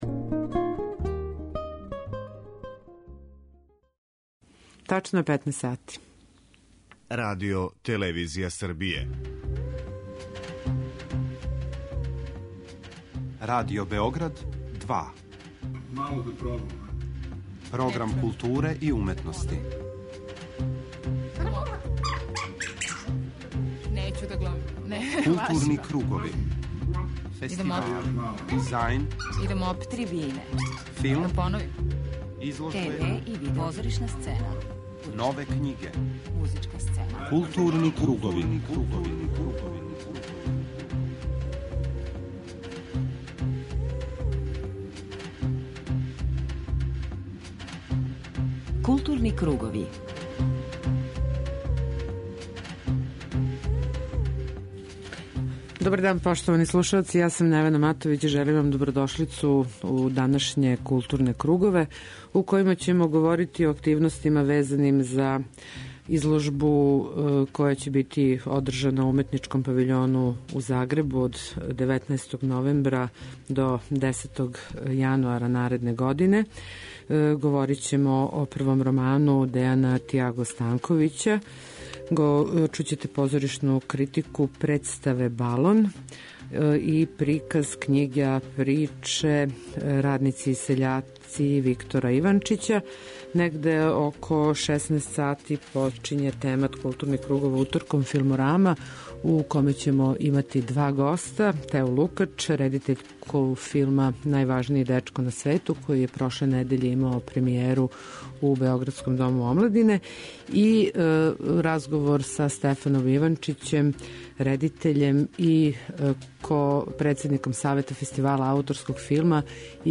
преузми : 41.15 MB Културни кругови Autor: Група аутора Централна културно-уметничка емисија Радио Београда 2.